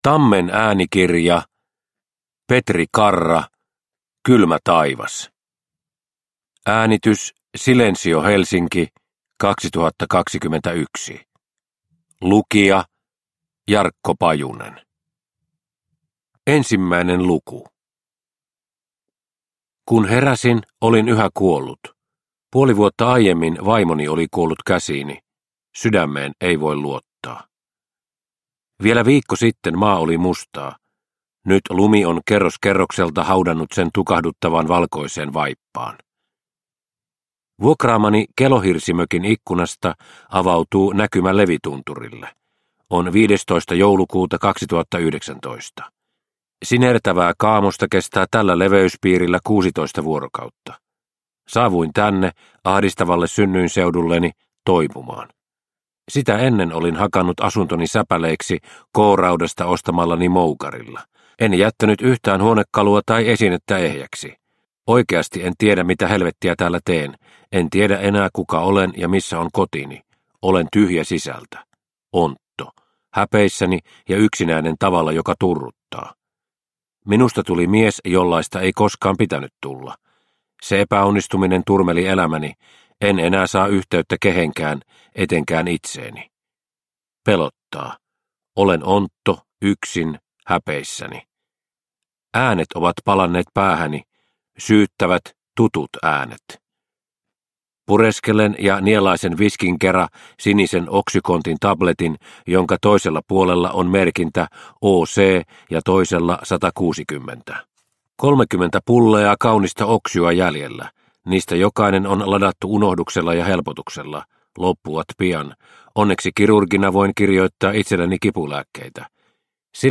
Kylmä taivas – Ljudbok – Laddas ner